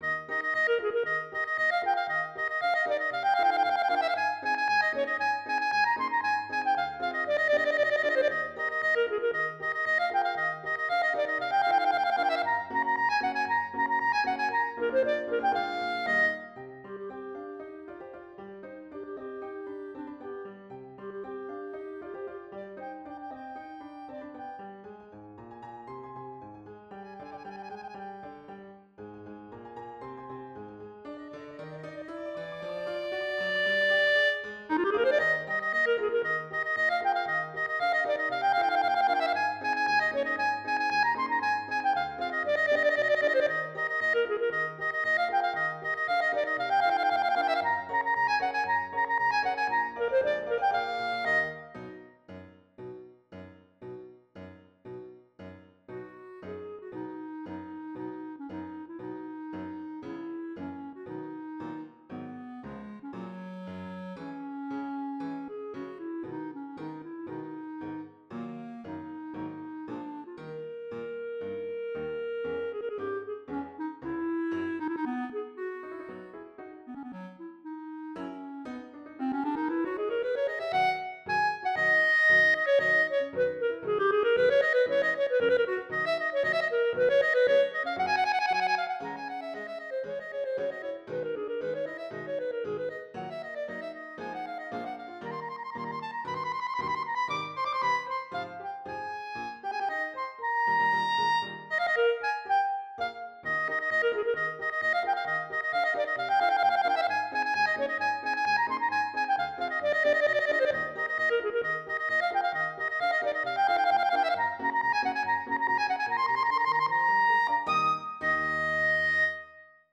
Voicing: Clarinet Solo